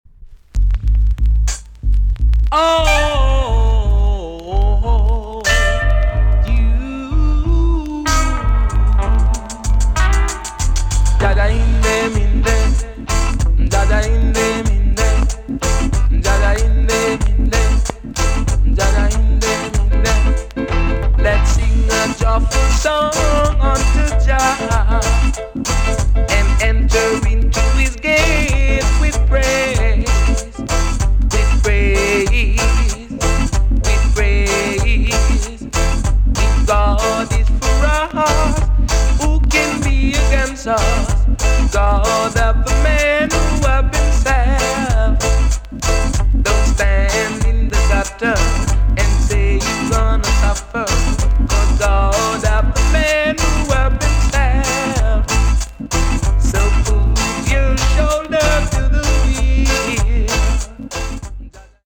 TOP >REGGAE & ROOTS
EX- 音はキレイです。
WICKED KILLER ROOTS TUNE!!